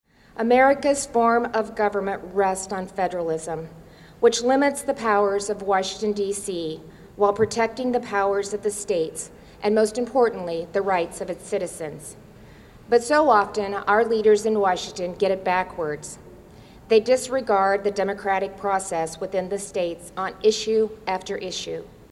Iowa’s New Attorney General Marks Start Of Term With Public Ceremony